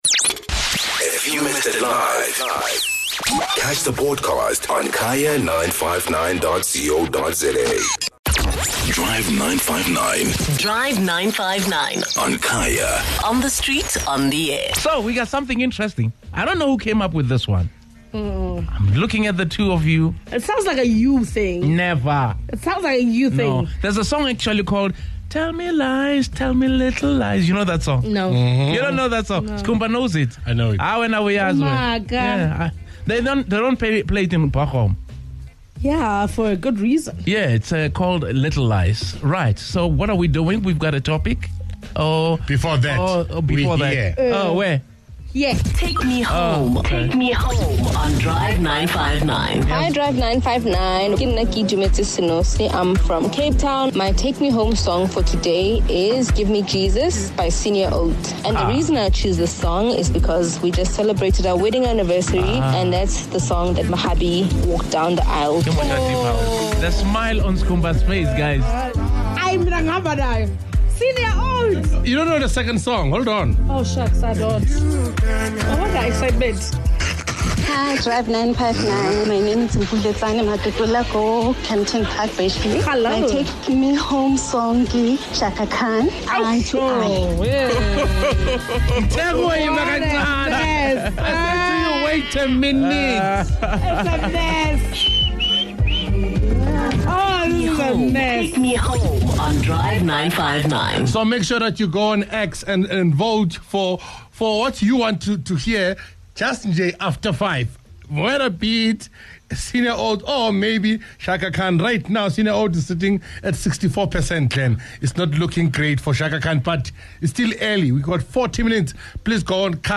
The Drive 959 listeners and the on-air team, share moments that they had under pressure, where they said something that wasn't entirely true, and watched it later backfire in hilarious ways!